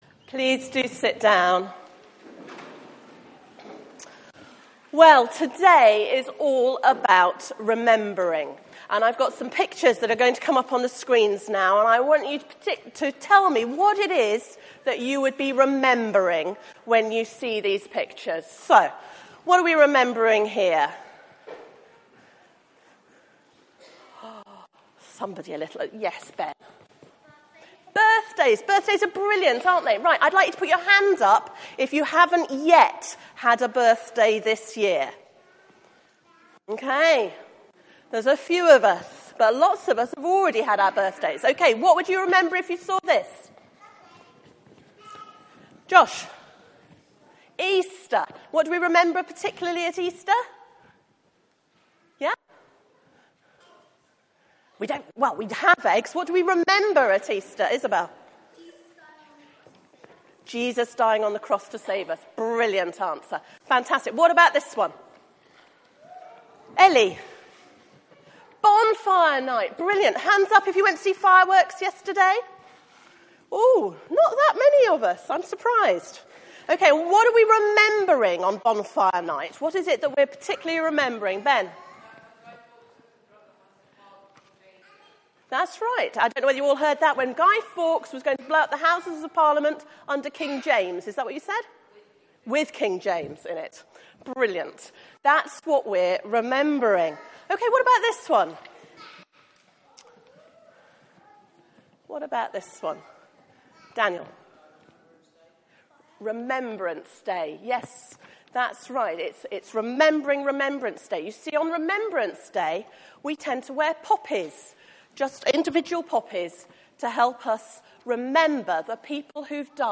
Media for 9:15am Service on Sun 08th Nov 2015
Sermon